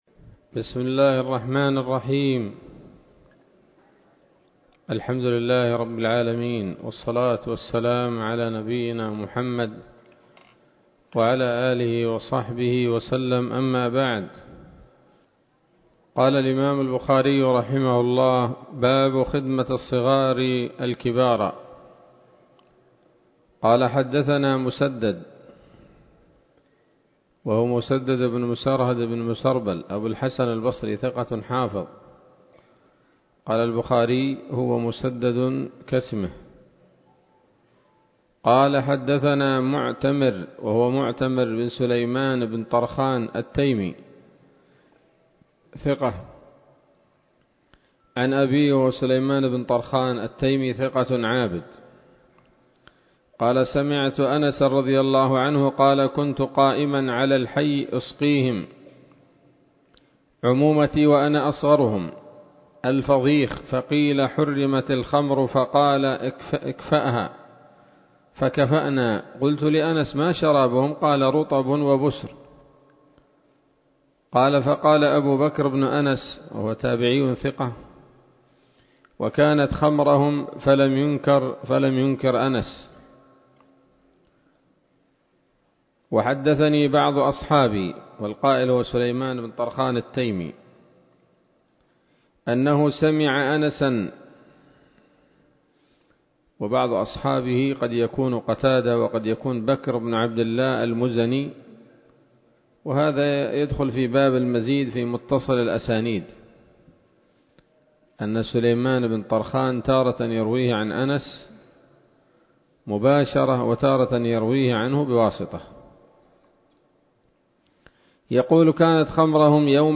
الدرس السادس عشر من كتاب الأشربة من صحيح الإمام البخاري